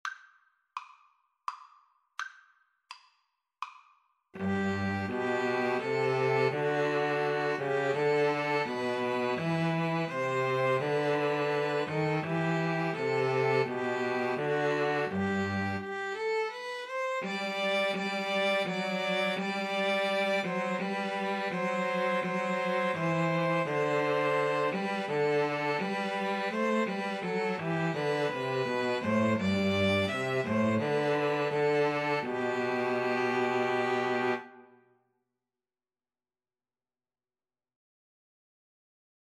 G major (Sounding Pitch) (View more G major Music for String trio )
3/4 (View more 3/4 Music)
String trio  (View more Easy String trio Music)